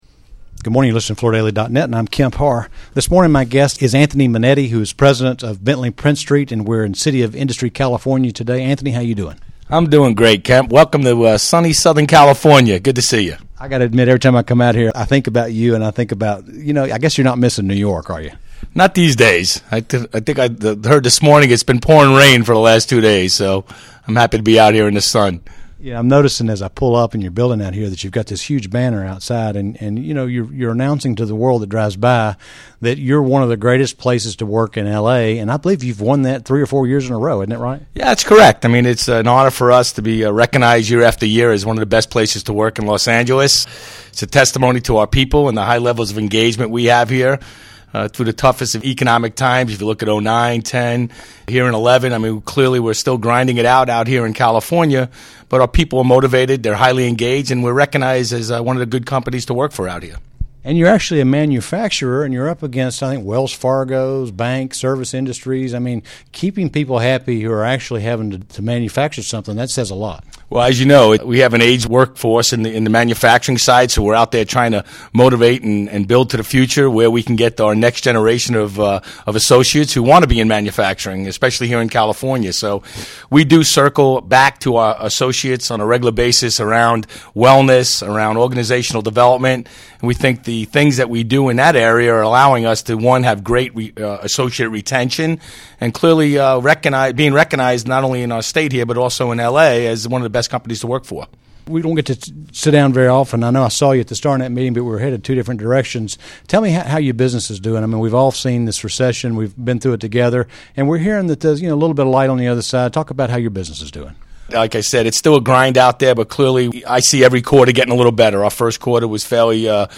Listen to the interview to hear where they are focusing their energy in 2011 to take advantage of the recovery we are seeing in the commercial market. Specifics include new tufting equipment, Ipad sales force tool, and employee developent.